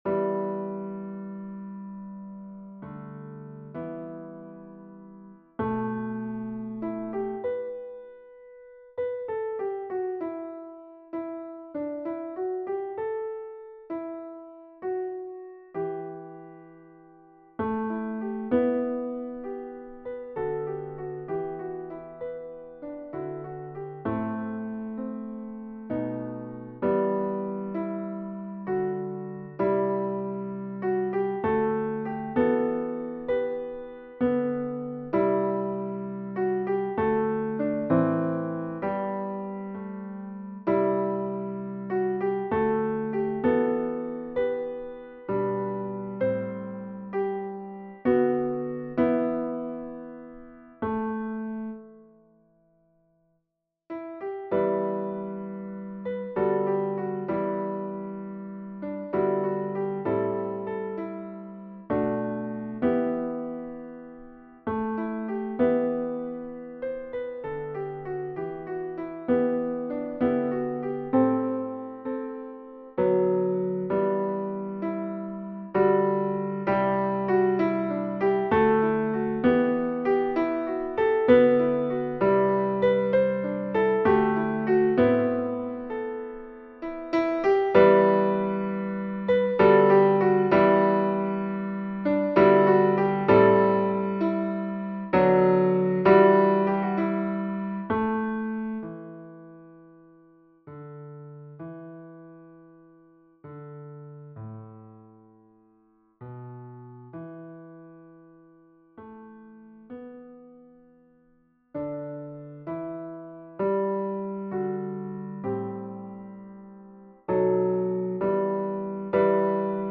Versions "piano"
Tenor 2